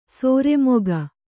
♪ sōre moga